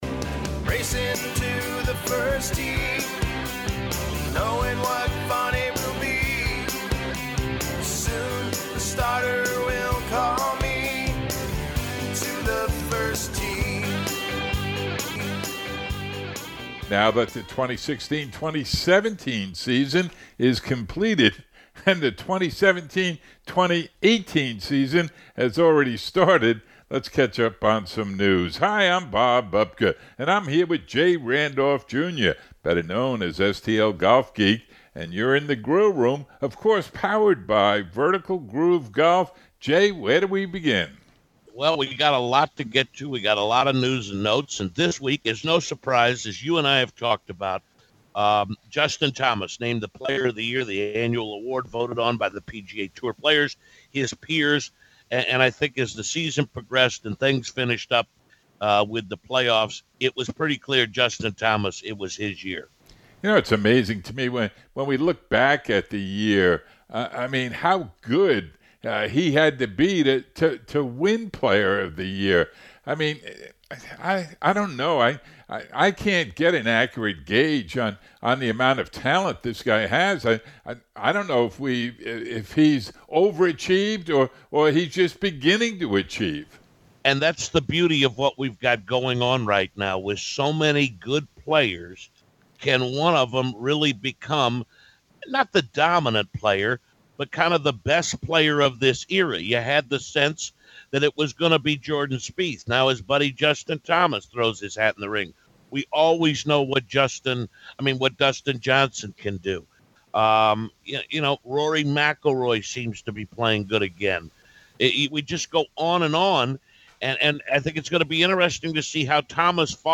Brendan Steele talks about his win at the Safeway Open in the 2017-2018 Season Opener on the PGA TOUR. Then it's catch-up time on the news in this edition of The Grill Room. Finally, a special feature interview